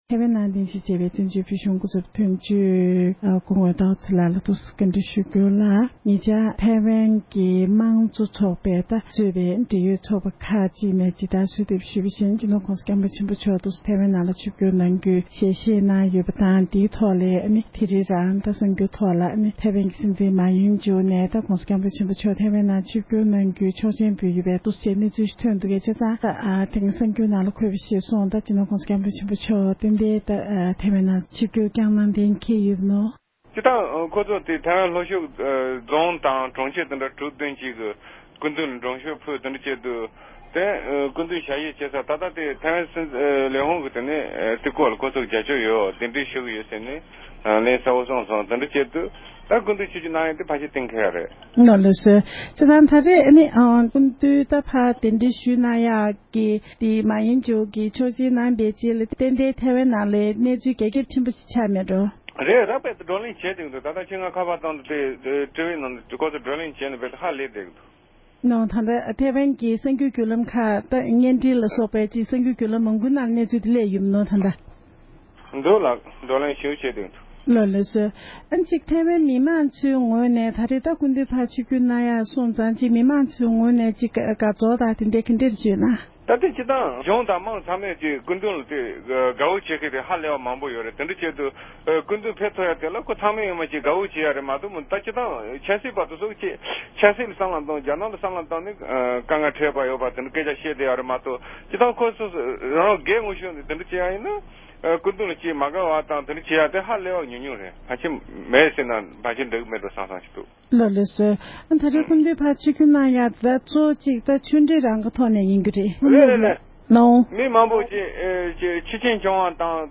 འབྲེལ་ཡོད་མི་སྣ་ཞིག་ལ་བཀའ་འདྲི་ཞུས་པ་ཞིག་ལ་གསན་རོགས༎